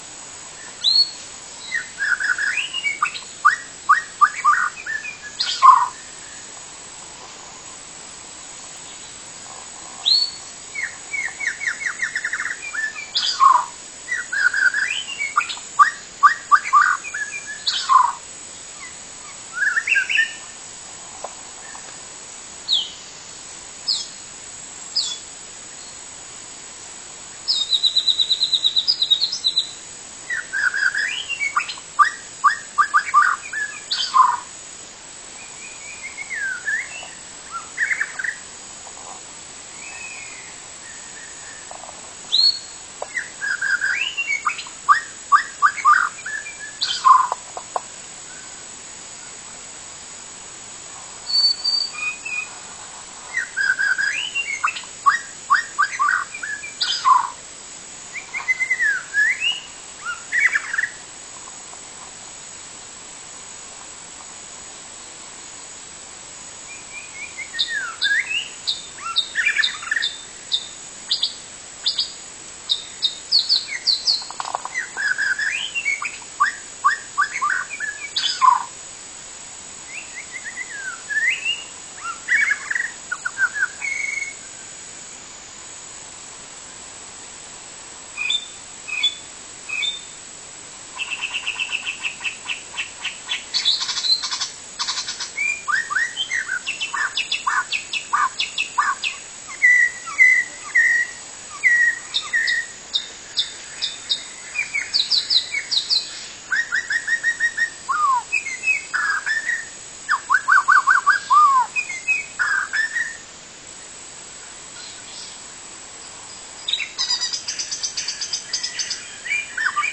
Berikut ini kumpulan audio murai batu alam super gacor yang bisa sobat download.
>> Murai Alam gacor 02